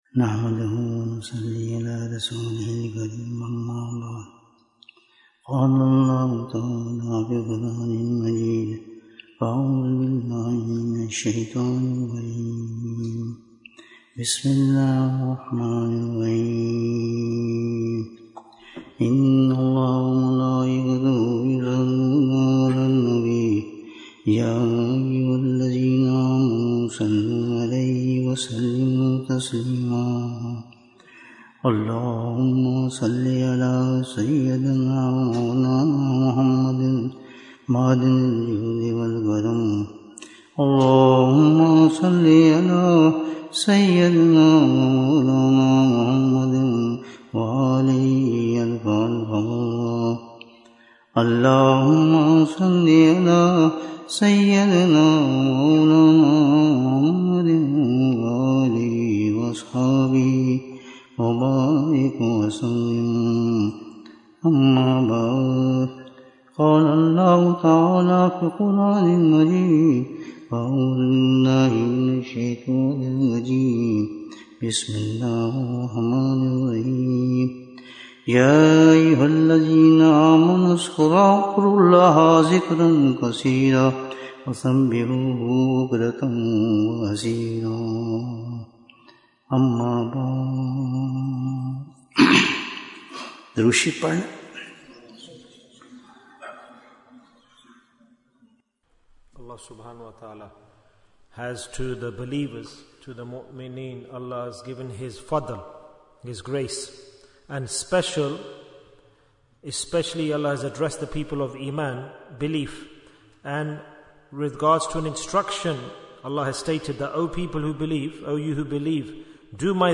Majlis-e-Dhikr in Bradford Bayan, 99 minutes18th January, 2025